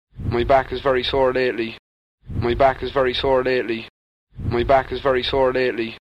Lack of R-retroflexion with local Carlow speaker
Carlow_SORE-NoRetroflexion.mp3